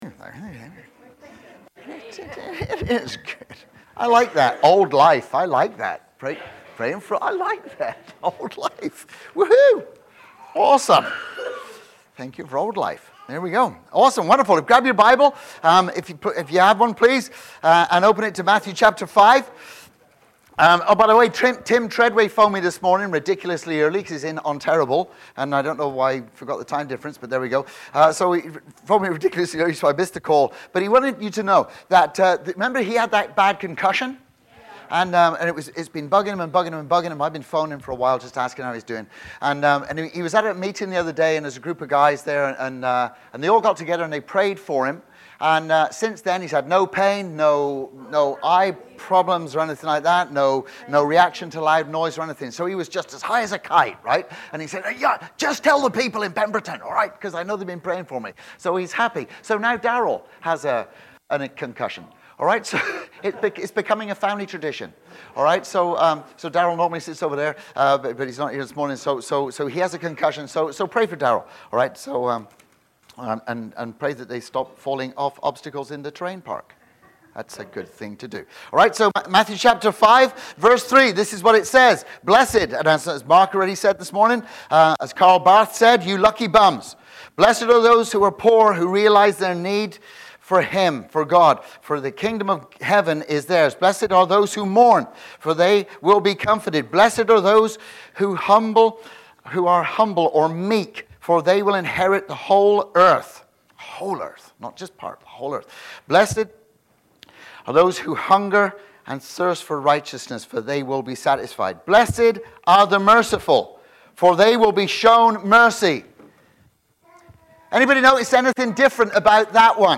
Sermons | Pemberton Community Church